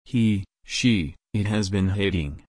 /heɪt/